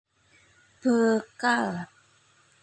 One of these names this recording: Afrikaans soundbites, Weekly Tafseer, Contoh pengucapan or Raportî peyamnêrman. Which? Contoh pengucapan